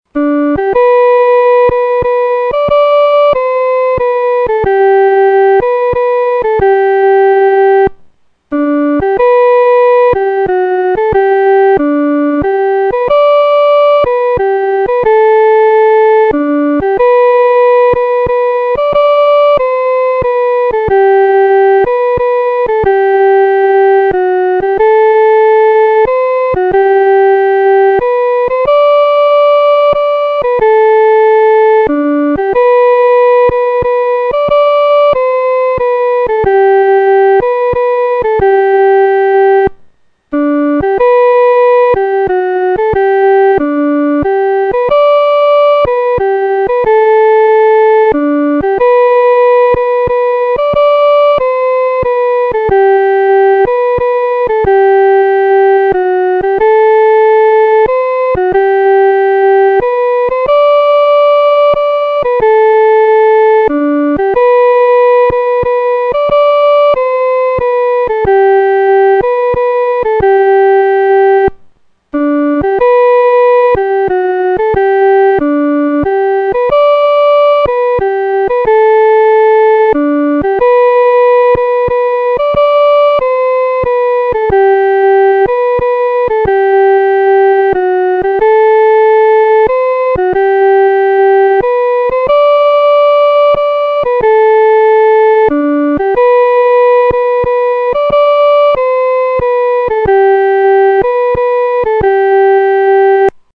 独奏（第一声）
靠近主-独奏（第一声）.mp3